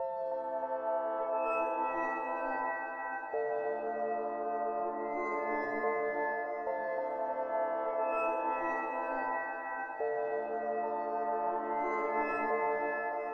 Watch Out_Piano.wav